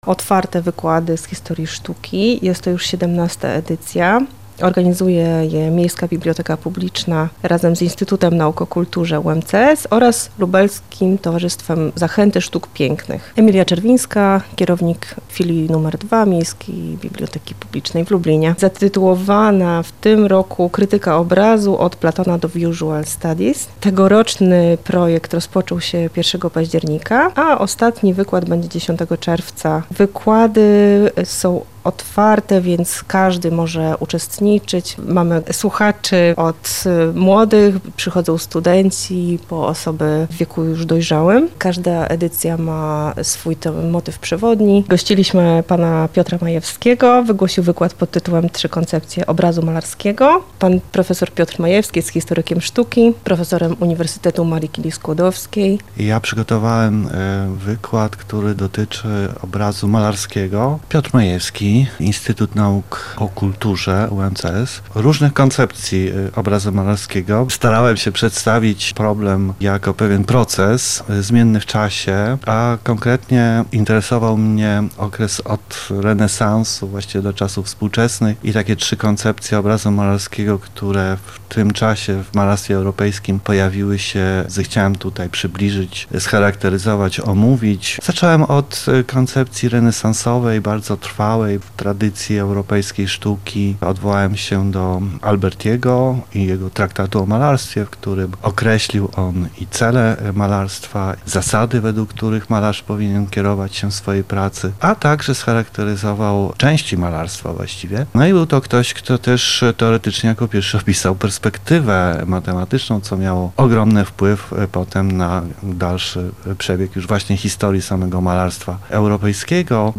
W Filii nr 2 Miejskiej Biblioteki Publicznej w Lublinie odbyło się kolejne spotkanie w ramach XVII już edycji otwartych wykładów z historii sztuki.